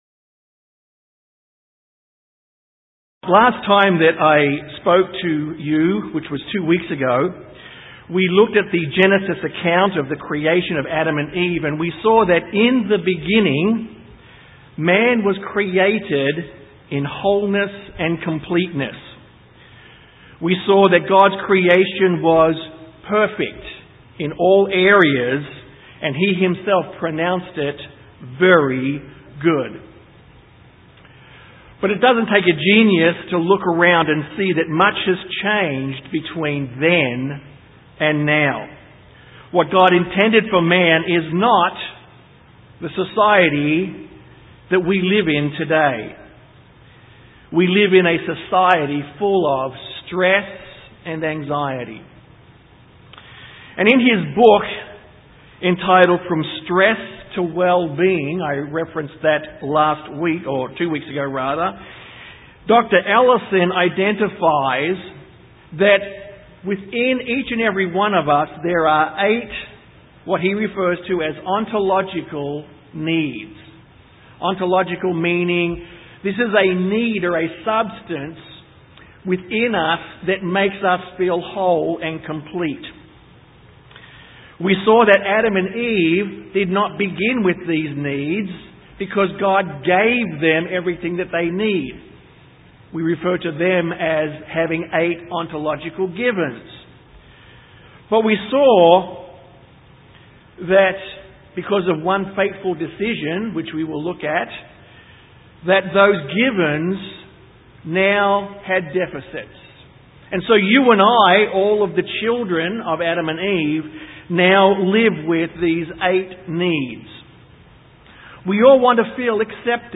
The truth however is that these holes are spiritual in nature and therefore only God can completely fill and heal them. This sermon focuses on how the holes can only be healed by Returning to God and allowing Christ to live in you.